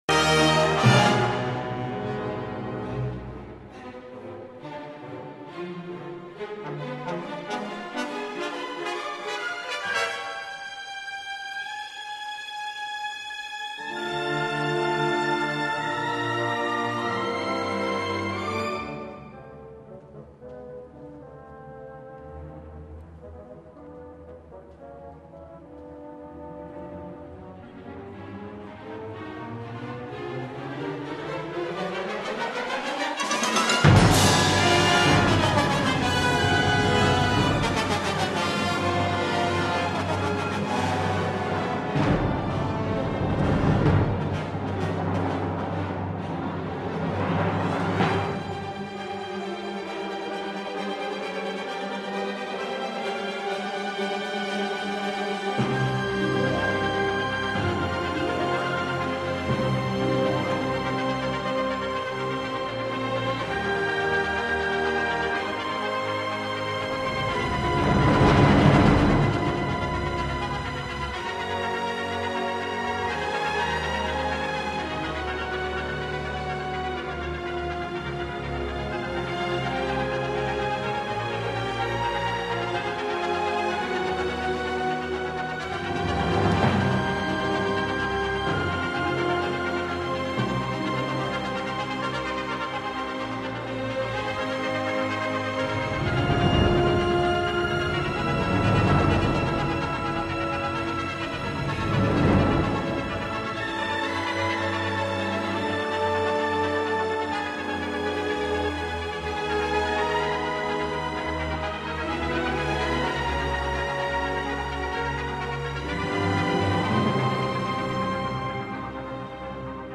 {historischer Verismo}
Interludio orchestrale
mascagni - nerone - interludio orchestrale.mp3